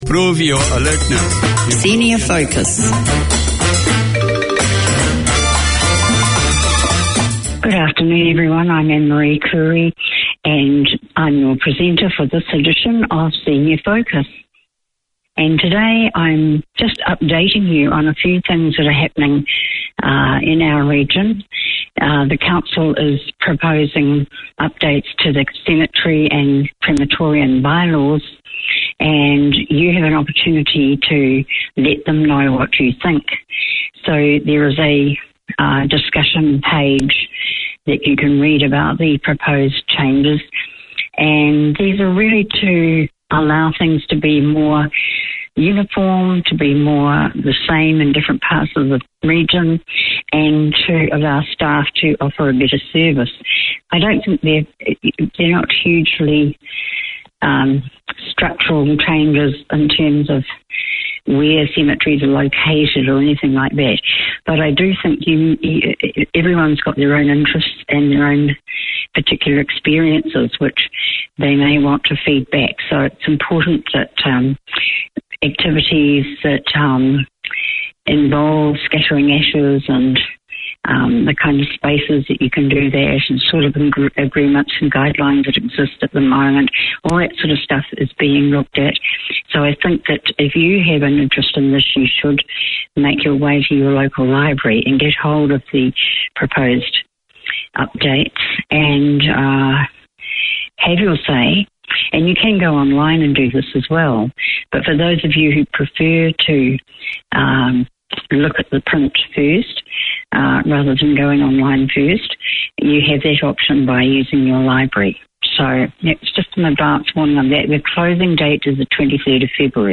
This half hour series features interviews and discussions on matters of interest to women in general and migrant women in particular. Women working in the community talk to women with shared experiences, to people who can help, to female achievers.